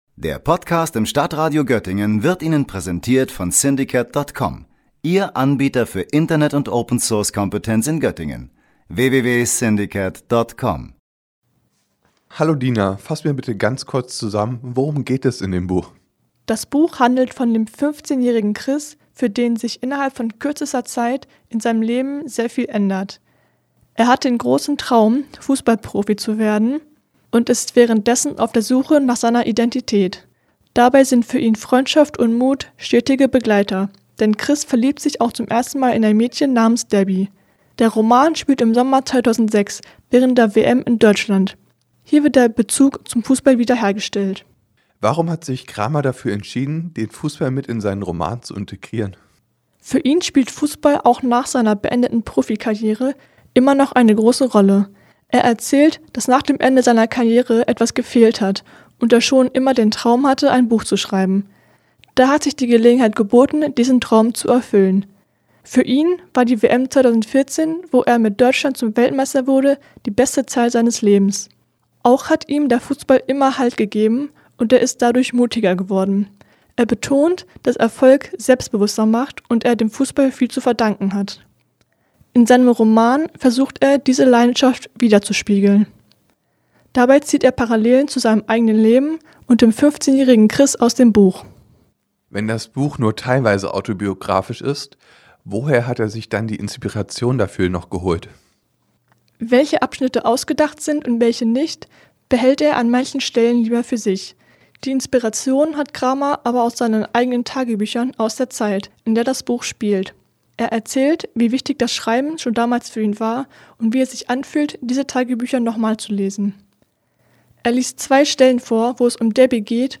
Beim vergangenen Literaturherbst war der Ex-Fußballspieler Christoph Kramer bei uns zu Besuch in Südniedersachsen. Der ehemalige Profisportler ist jetzt unter die Autoren gegangen.